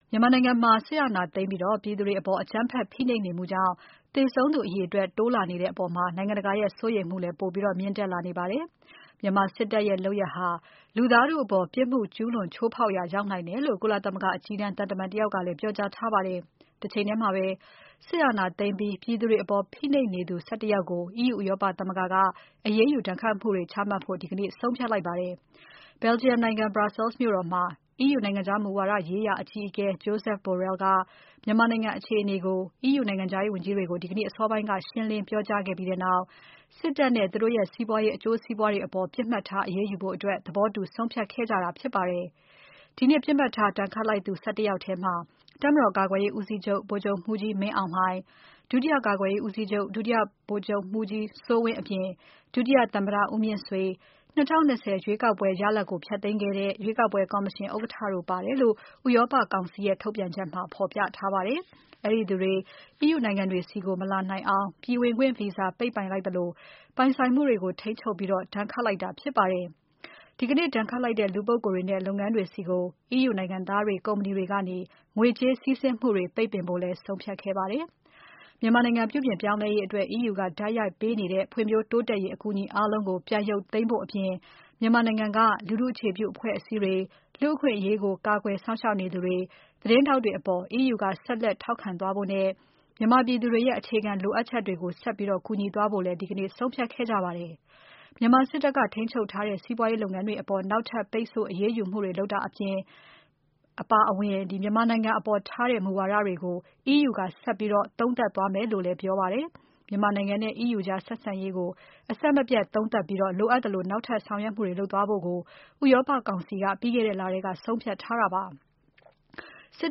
ကုလသမဂ္ဂကနေ မြန်မာနိုင်ငံအပေါ် R2P လုပ်ငန်းစဉ်တွေ စတင်ပေးဖို့ တောင်းဆိုတဲ့ စာသားတွေ ချိတ်ဆွဲထားတဲ့ မိုးပျံပူဖောင်း အနီရောင်တွေကို လွှတ်တင်ဆန္ဒပြကြတဲ့ အသံတွေဖြစ်ပါတယ်။